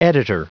Prononciation du mot editor en anglais (fichier audio)
editor.wav